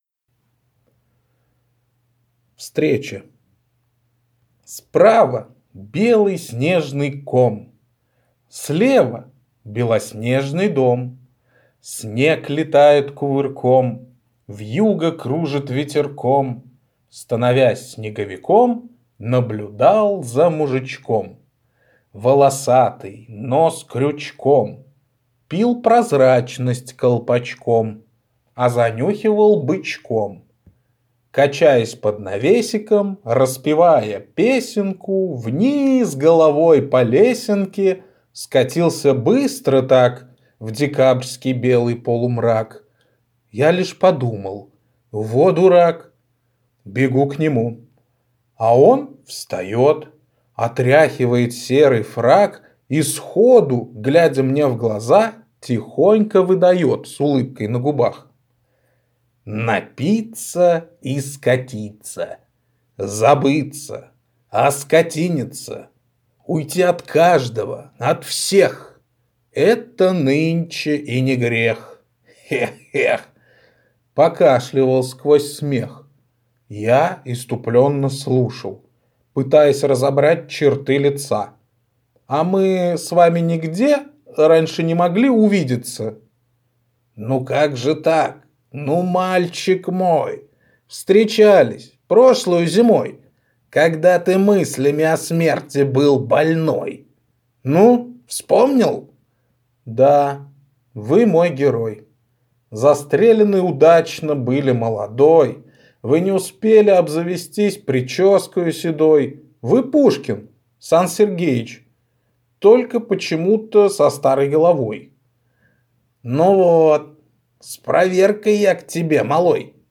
Аудиокнига Записки самозанятого добровольца | Библиотека аудиокниг